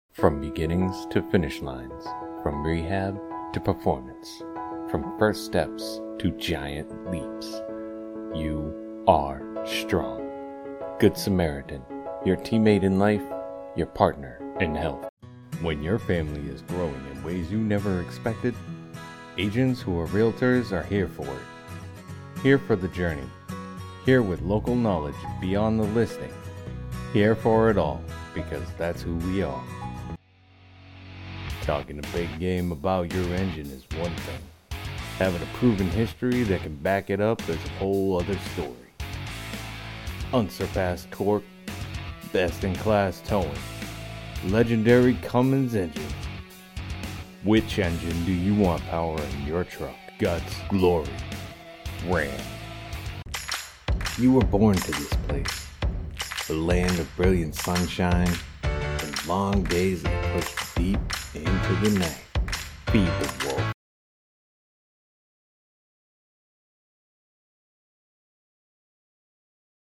Commercial Voice Overs
Engage your audience with captivating voices for TV, radio, and online ads.